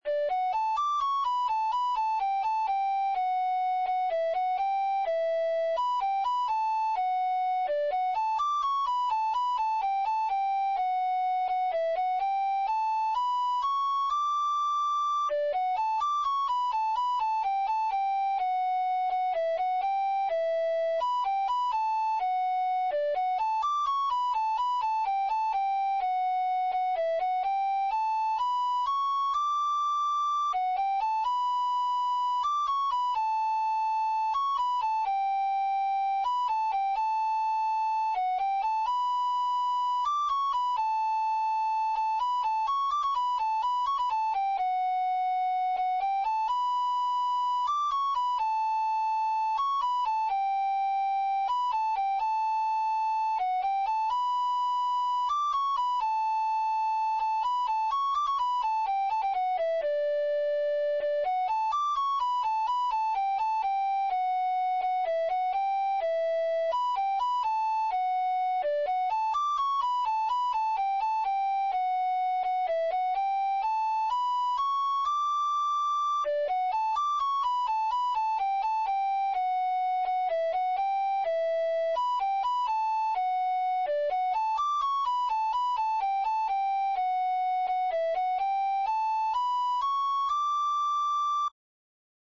Pasodobres – Páxina 2 – Pezas para Gaita Galega
Pasodobres
Seran uns arquivos mp3. moi sinxelos xerados a partires dun midi, máis para darse unha idea de como soa o tema agardo que sirvan.
Primeira voz.